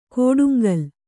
♪ kōḍuŋgal